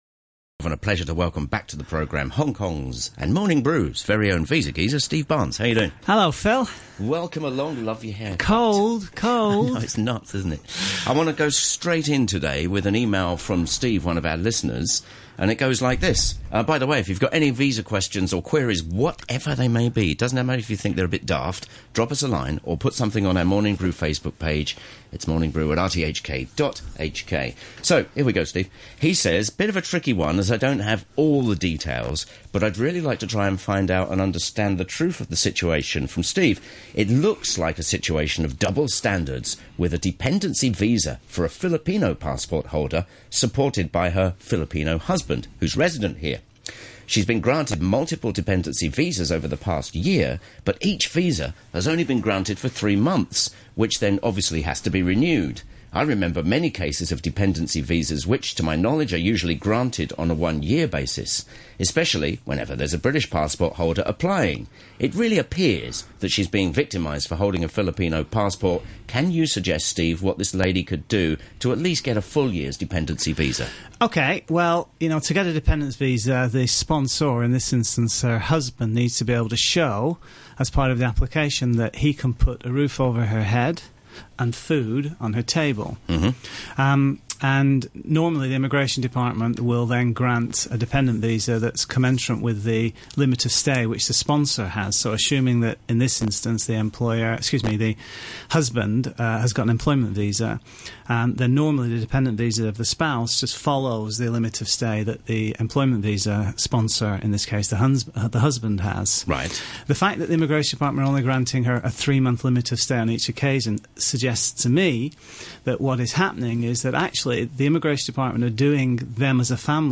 Freezing at RTHK today…